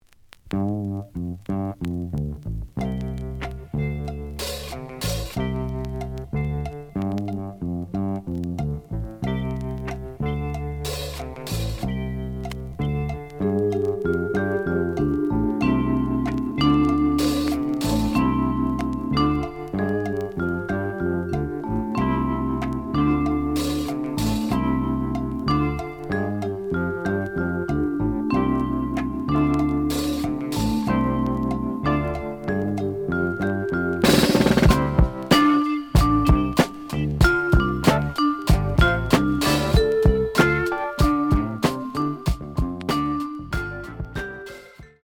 The audio sample is recorded from the actual item.
●Genre: Jazz Funk / Soul Jazz
B side plays good.)